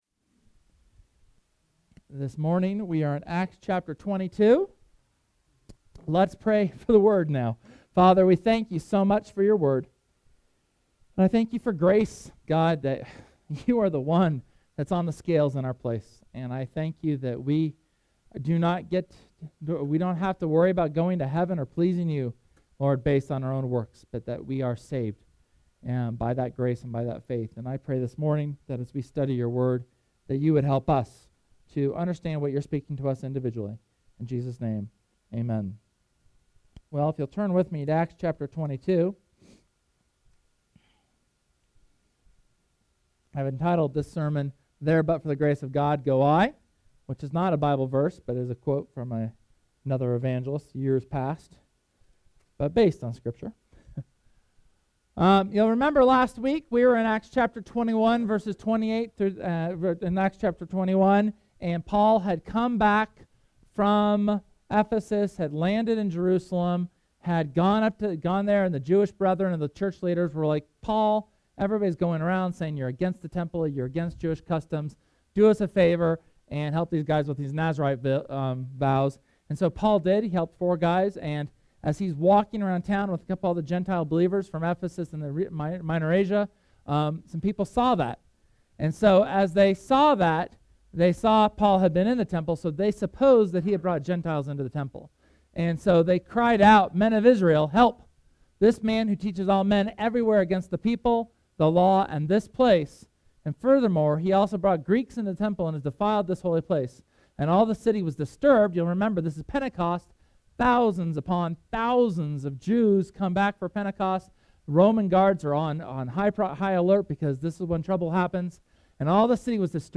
SERMON: There but for the grace of God go I…
Sermon on Acts chapter 22 when Paul is rejected by his Jewish brothers because of the Gospel.